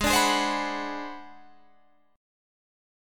G#7#9b5 chord